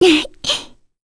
Kirze-Vox-Laugh_kr.wav